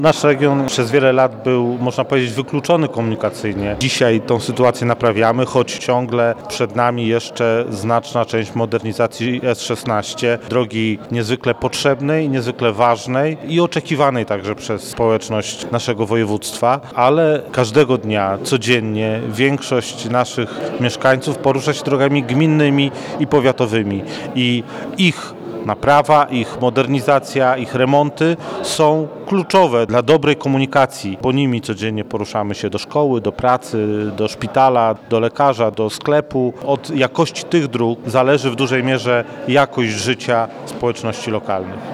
– Przygotowujemy się do modernizacji drogi ekspresowej S-16, ale nie można zapominać o mniejszych, używanych każdego dnia drogach – mówi wojewoda warmińsko-mazurski Artur Chojecki.